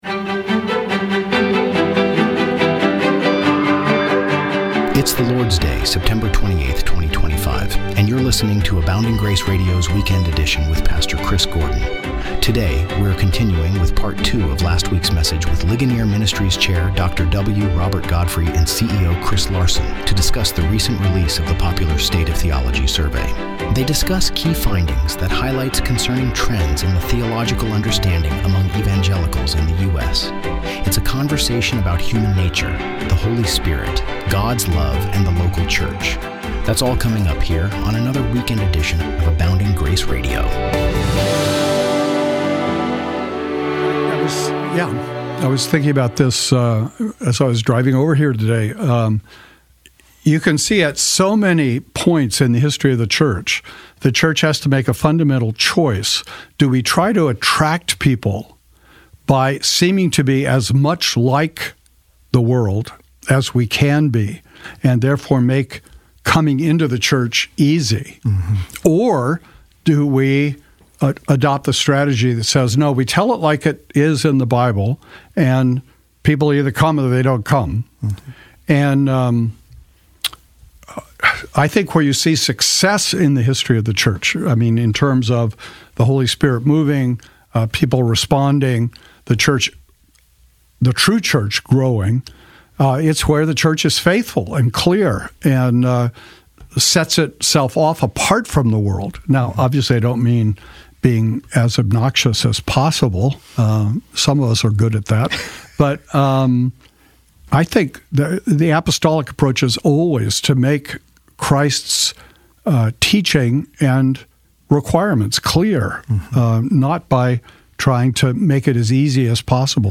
They delve into the survey's findings, highlighting concerning trends in theological understanding among evangelicals in the US. The conversation covers a range of topics, including views on human nature, the Holy Spirit, God's love, religious pluralism, and the importance of local church membership. They also touch upon the broader societal implications of theological decline and Ligonier's efforts to promote robust biblical understanding.